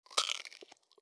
mixkit-meat-hit.wav